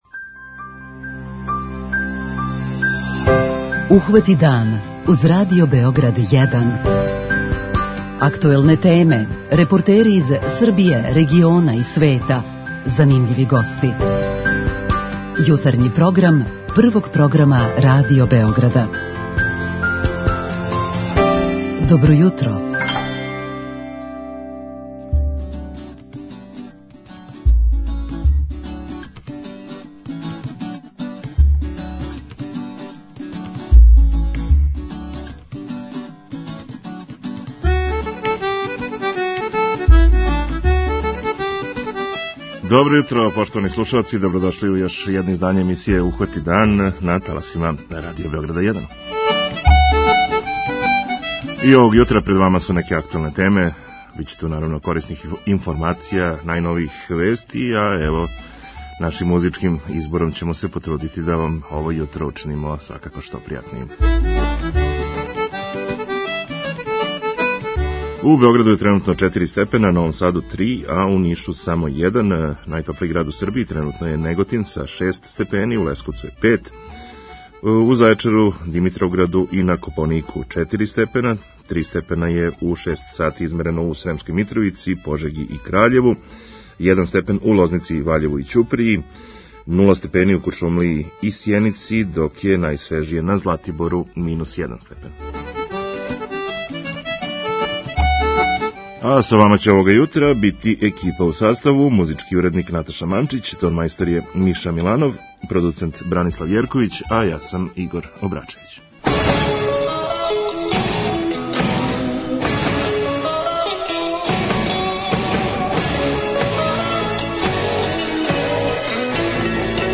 Такође, то је и тема нашег Питања јутра, замолићемо вас да са нама поделите корисне савете о томе како да бацамо што мање хране. преузми : 37.78 MB Ухвати дан Autor: Група аутора Јутарњи програм Радио Београда 1!